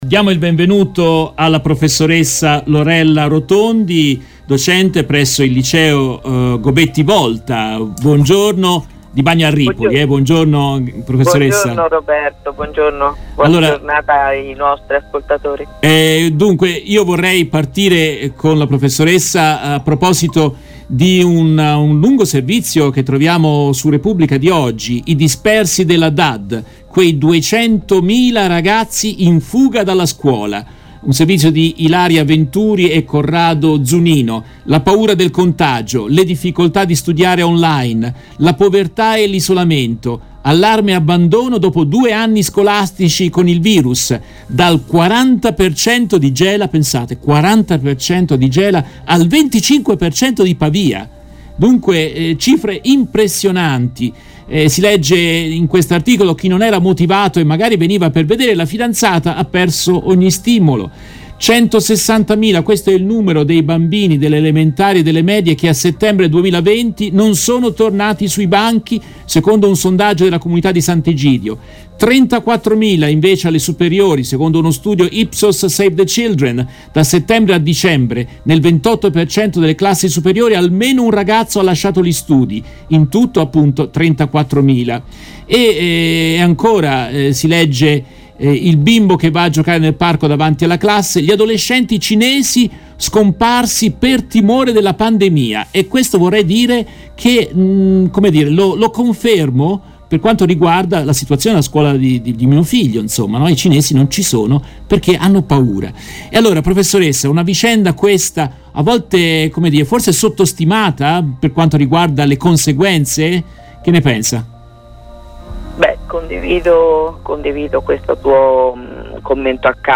In questa intervista, tratta dalla trasmissione in diretta del 17 maggio 2021